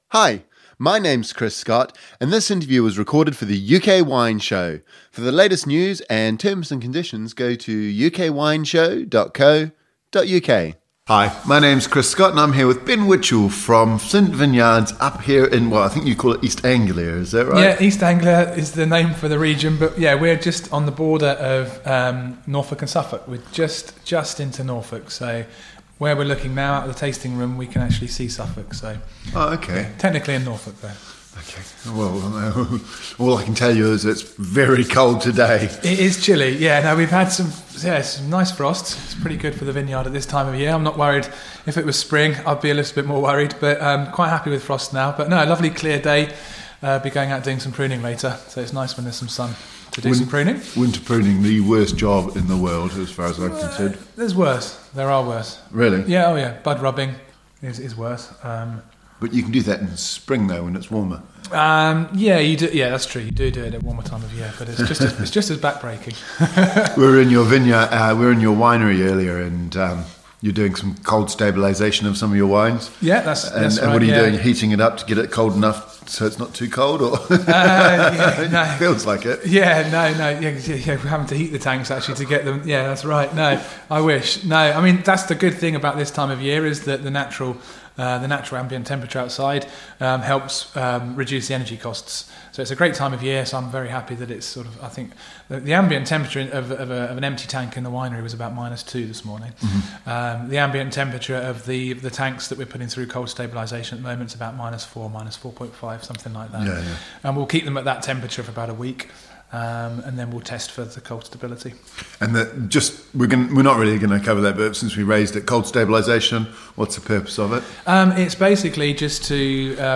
UK Wine Show » Listen to the interview only